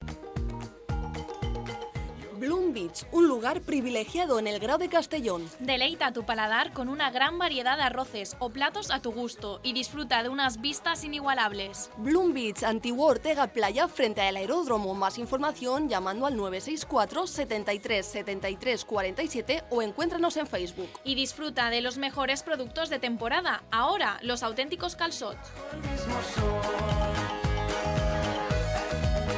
Seguro que lo sabes, pero si escuchas la cuña podrás conocer más sobre el nuevo lugar privilegiado en el Grau de Castellón.